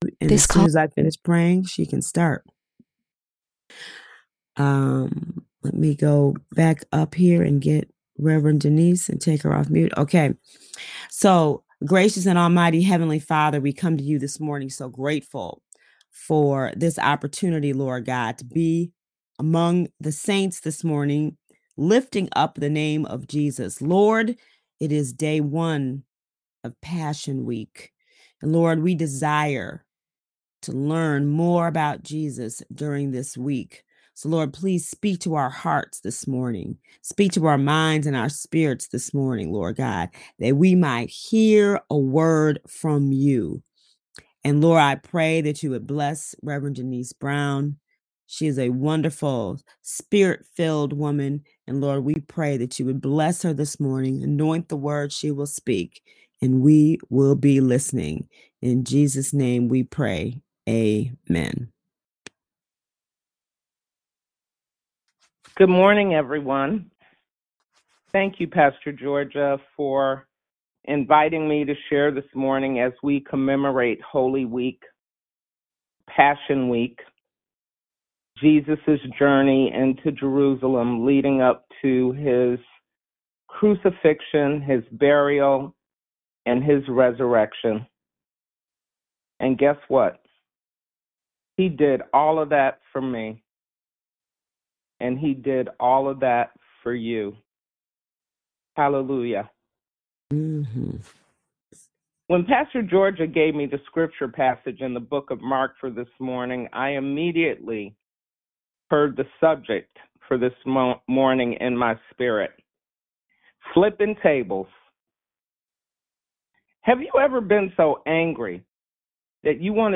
This wasn’t just a prayer—it was a charge.